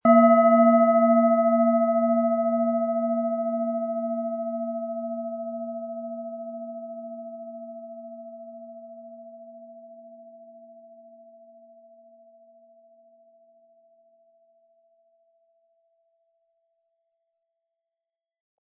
Planetenschale® In den Bauch spüren & Sich selbst helfen können mit Mond, Ø 11,1 cm, 100-180 Gramm inkl. Klöppel
Planetenton 1
Mit dem beiliegenden Klöppel wird Ihre Klangschale mit schönen Tönen klingen.
MaterialBronze